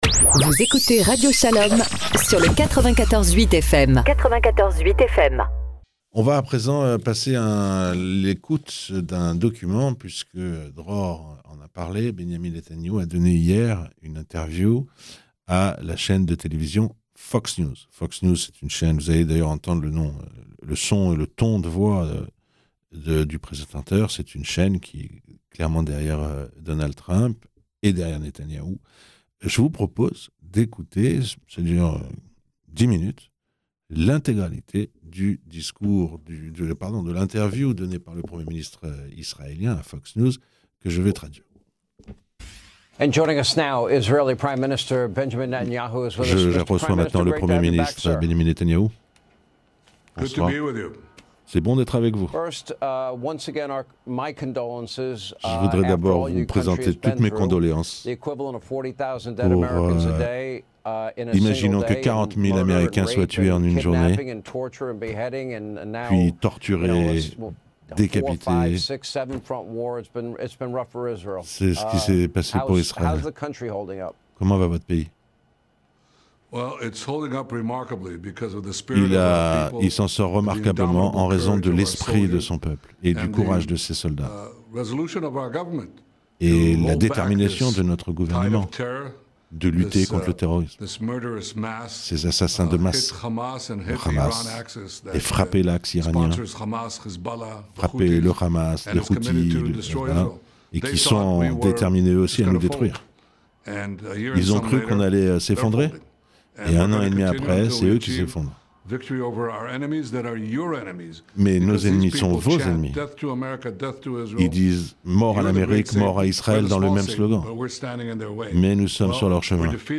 Ecoutez l'interview traduite en français de Netanyahou sur Fox News